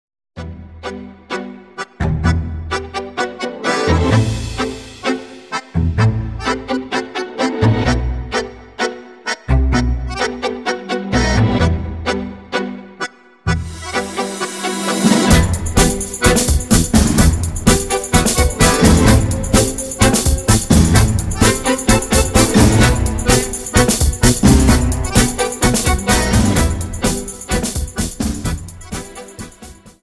Dance: Tango Song